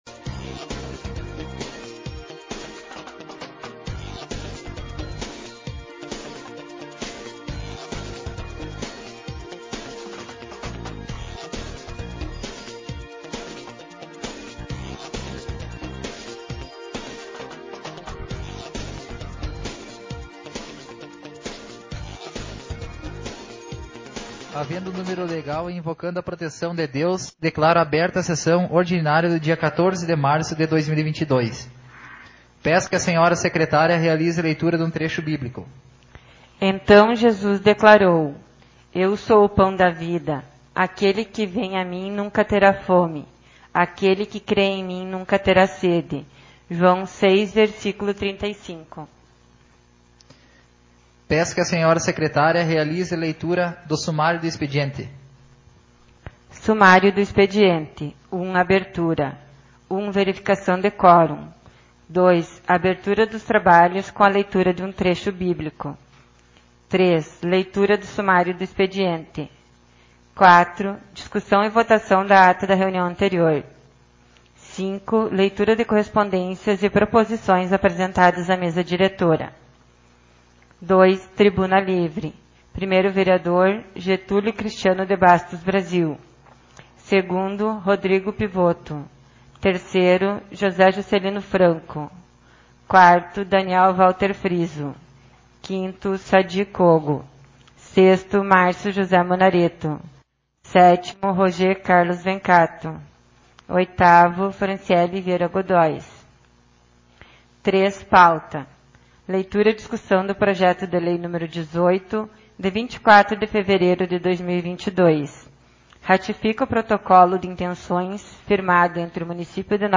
audio sessão — Câmara Municipal de Vereadores